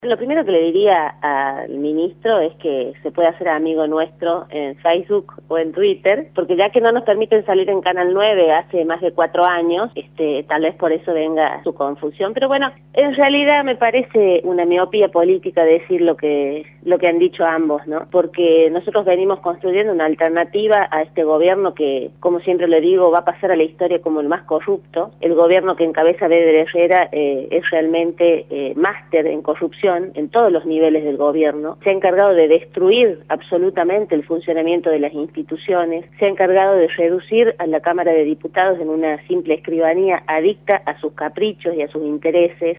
Inés Brizuela y Doria, diputada nacional, por Radio La Red